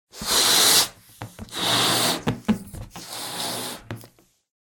balloonblow.ogg